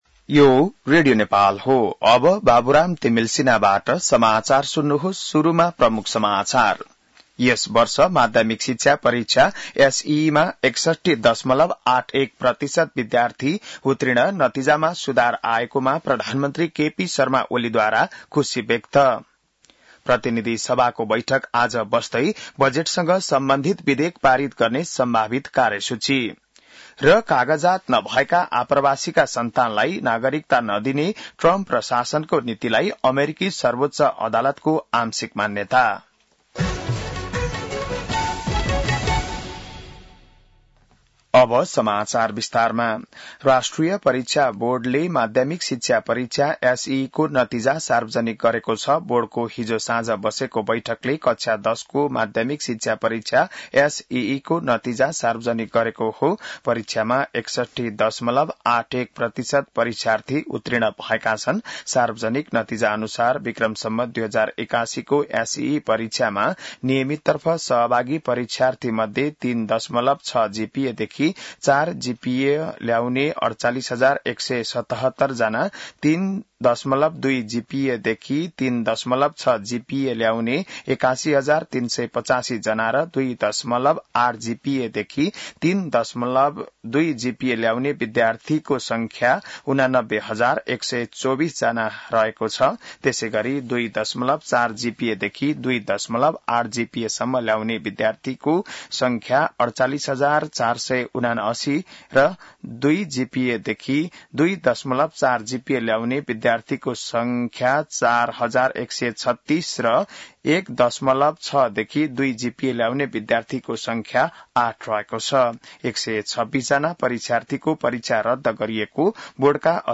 बिहान ९ बजेको नेपाली समाचार : १४ असार , २०८२